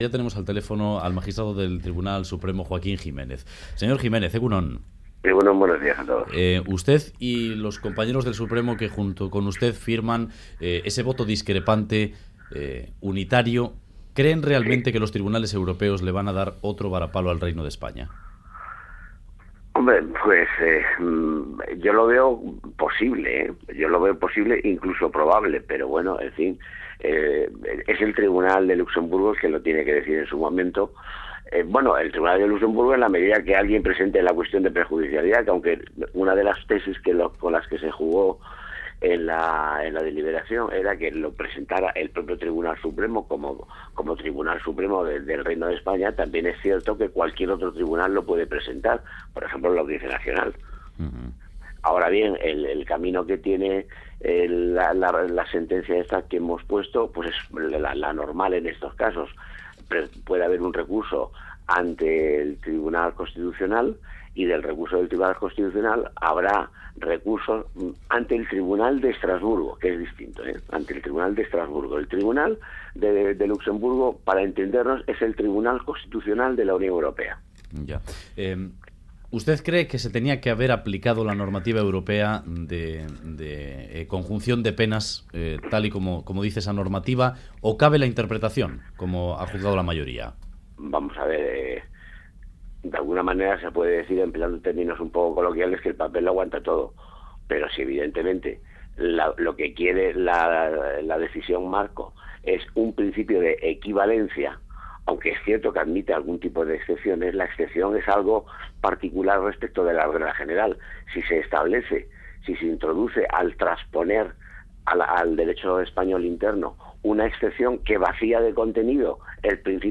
Radio Euskadi BOULEVARD Giménez: 'Es probable que Luxemburgo vuelva a fallar contra España' Última actualización: 19/02/2015 10:16 (UTC+1) En entrevista al Boulevard de Radio Euskadi, el magistrado del Tribunal Supremo, Joaquín Giménez, ha afirmado que es posible, incluso probable, que el Tribunal de Luxemburgo vuelva a fallar contra el Reino de España, en este caso por el no descuento a los presos de ETA de las penas cumplidas en otro país de la UE.